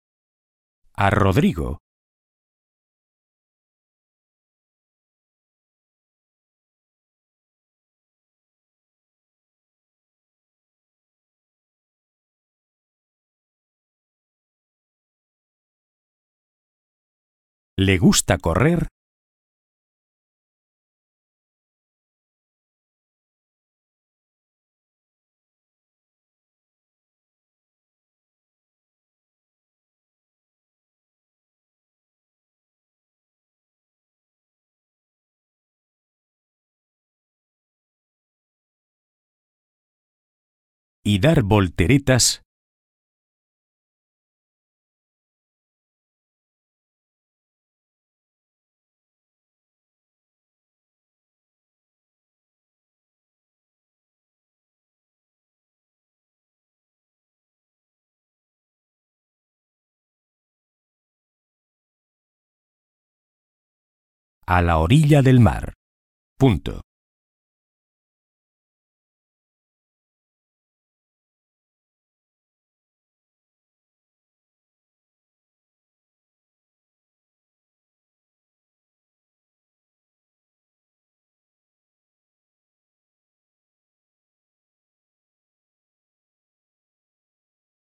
Hoy vamos a hacer un dictadito, fijándonos bien en todas las cosas que hemos aprendido hasta ahora.
Dictado-1.ogg